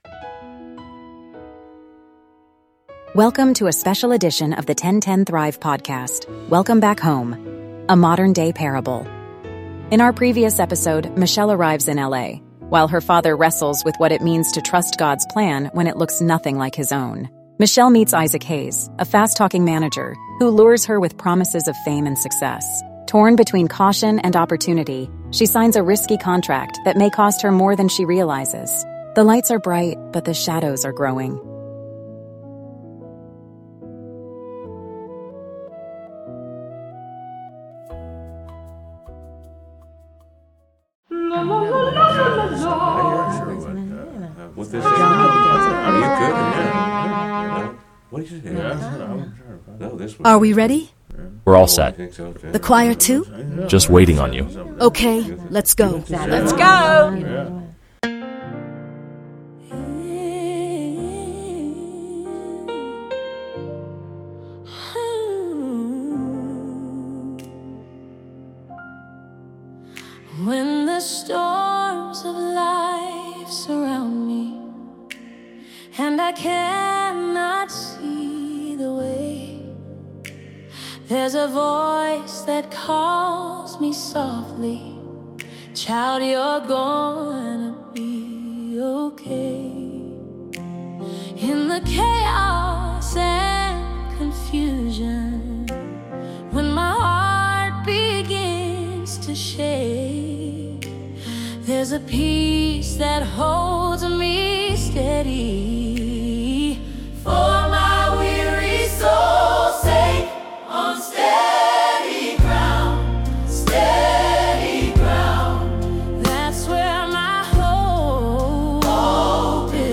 Her delivery is sincere and anchored in the church style that shaped her voice.